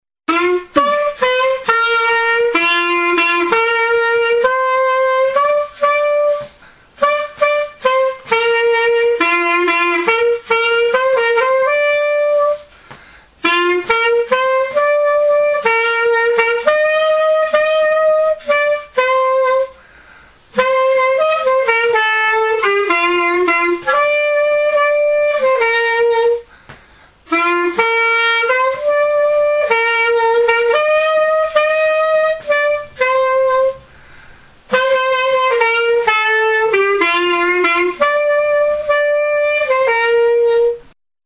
transkrypcja zapisu terenowego